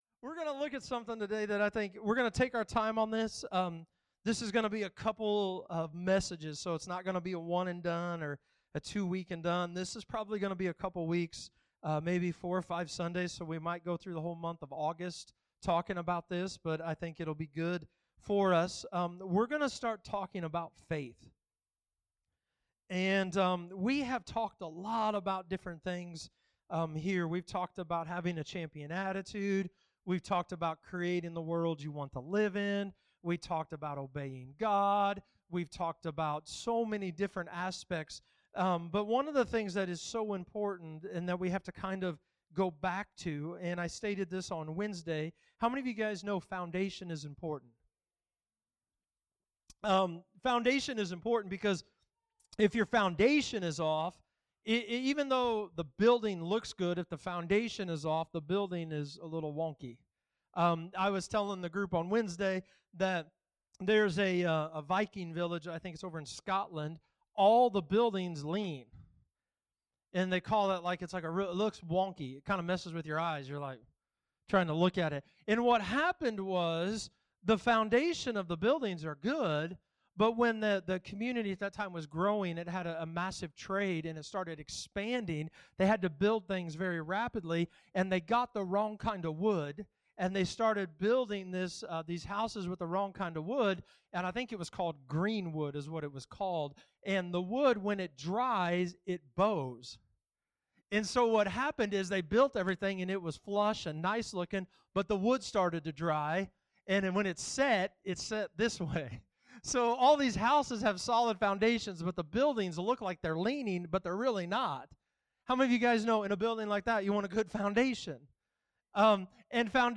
Sermons | LifePointe Church